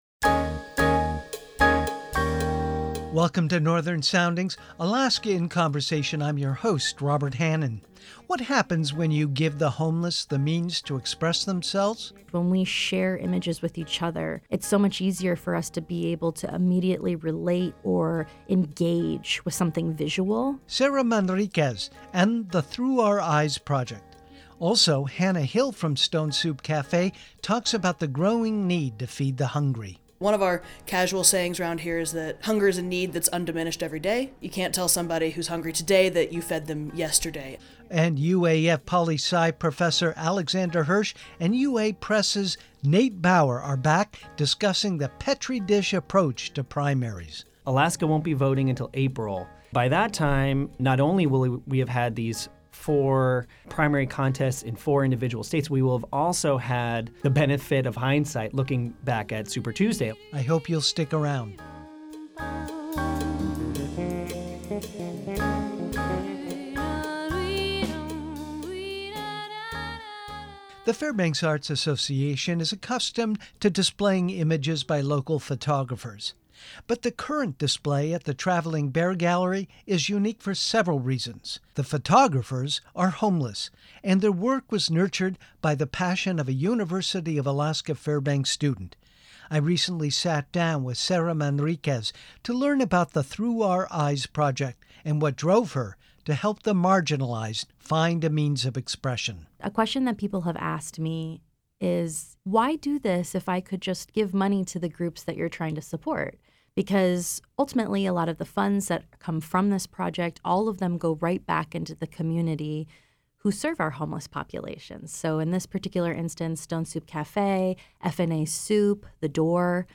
They analyze the developing presidential primary season from an Alaskan perspective.